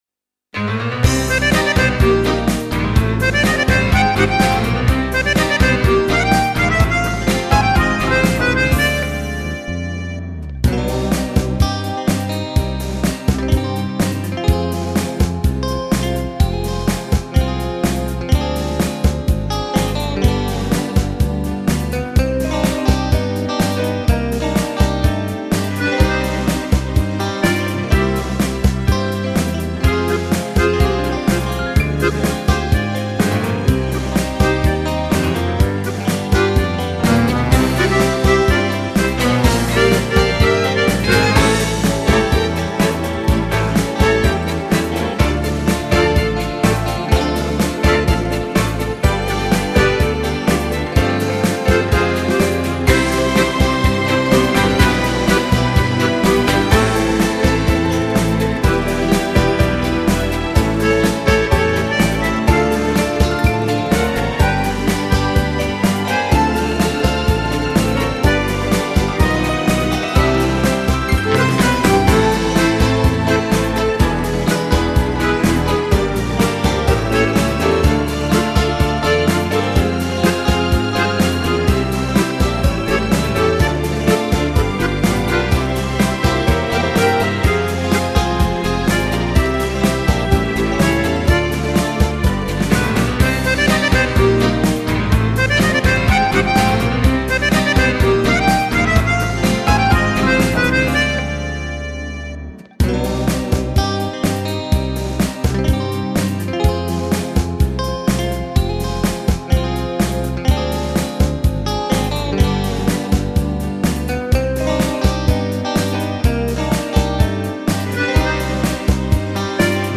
Genere: Beguine
Scarica la Base Mp3 (4,00 MB)